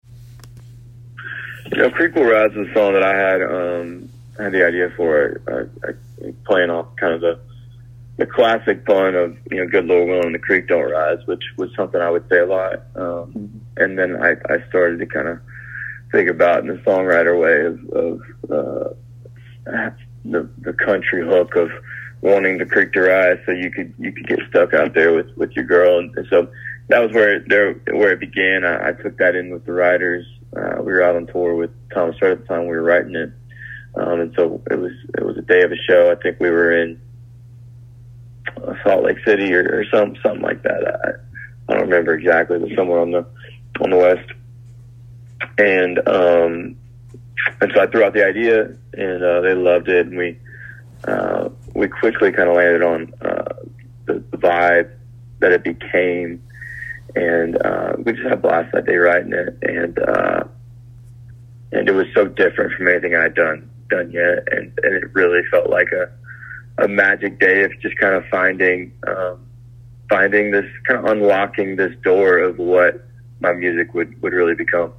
Here’s an excerpt of our interview with Conner Smith, who tells how he co-wrote with his hit, “Creek Will Rise.”
Conner_Smith_interview_excerpt.mp3